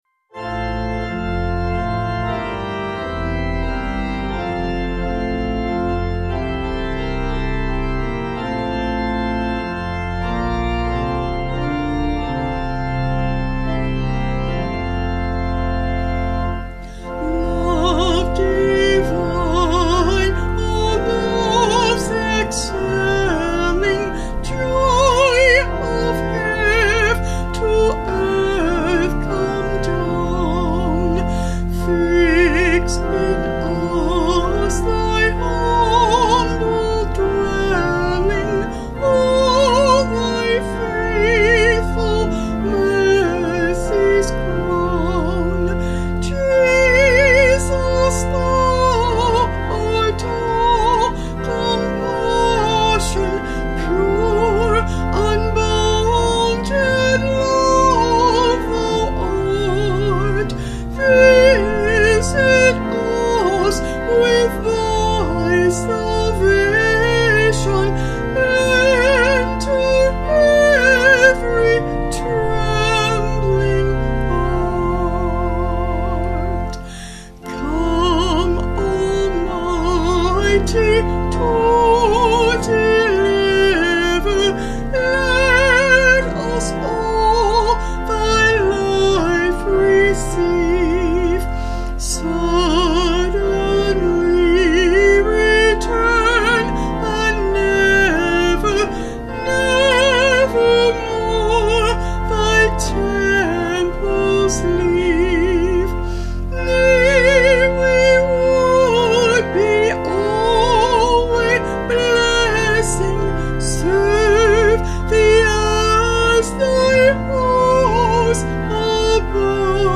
8.7.8.7.D
Vocals and Organ   291.2kb Sung Lyrics